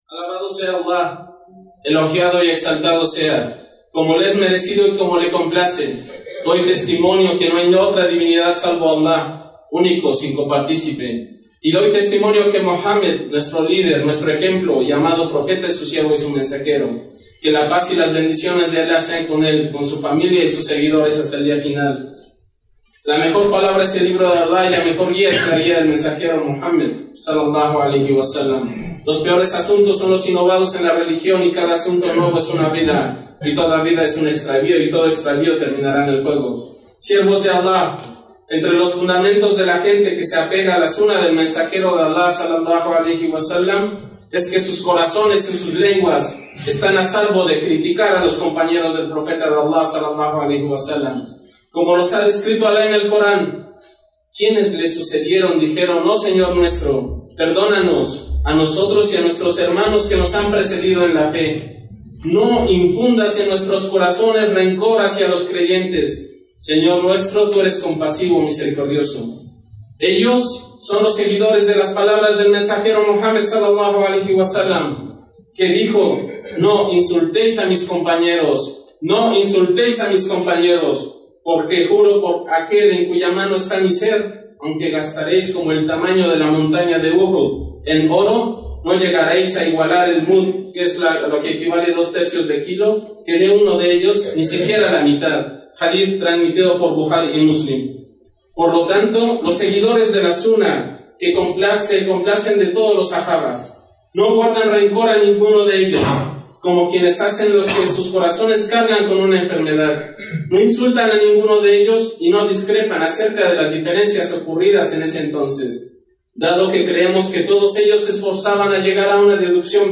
Jutbah sobre las virtudes de los compañeros del Profeta Muhammad, que Allah se complazca de ellos.